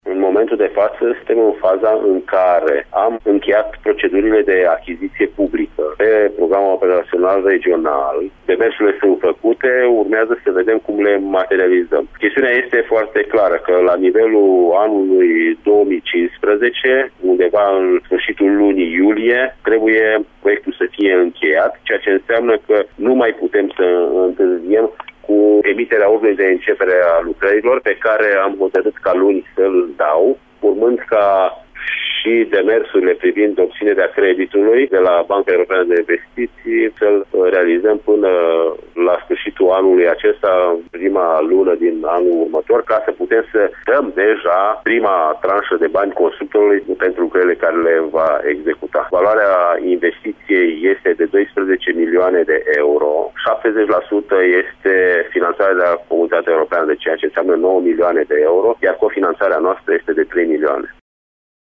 Primarul comunei Berzasca, Petru Furdui: